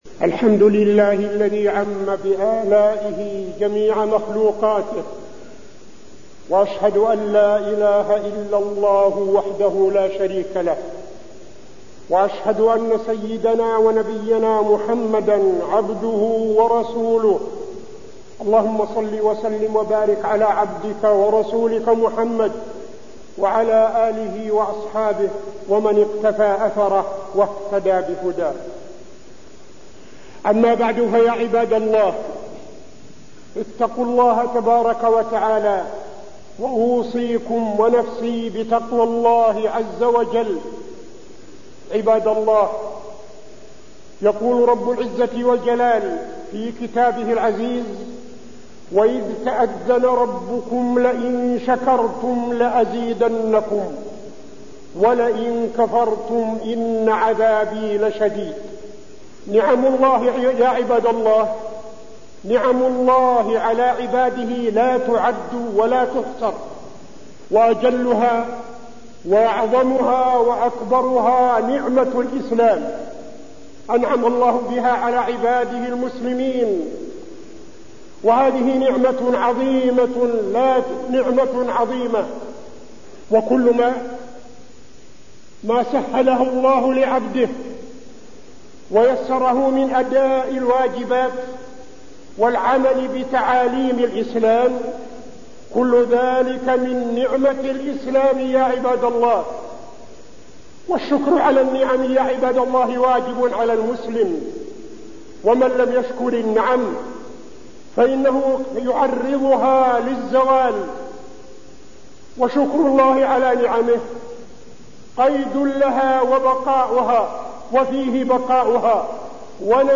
تاريخ النشر ٦ شوال ١٤٠٦ هـ المكان: المسجد النبوي الشيخ: فضيلة الشيخ عبدالعزيز بن صالح فضيلة الشيخ عبدالعزيز بن صالح شكر النعم